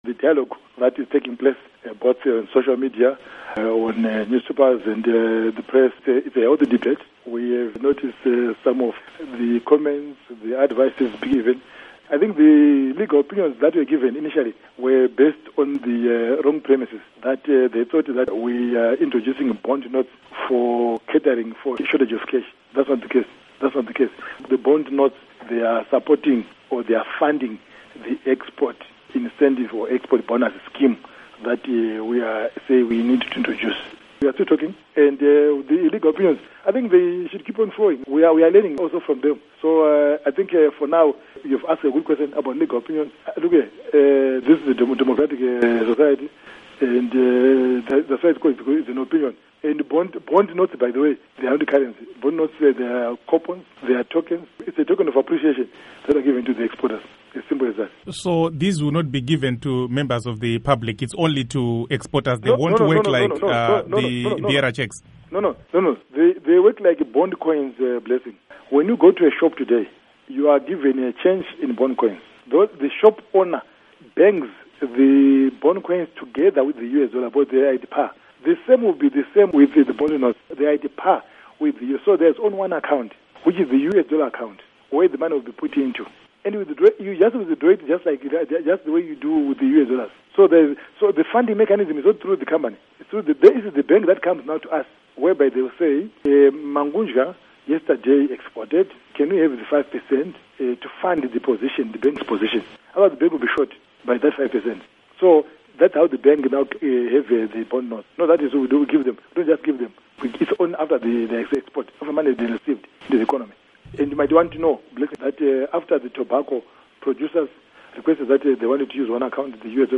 Interview With RBZ governor John Mangudya